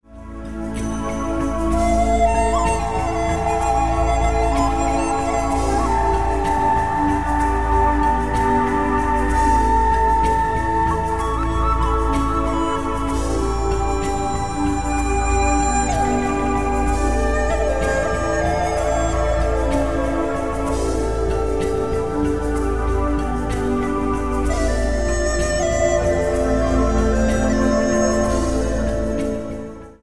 64 BPM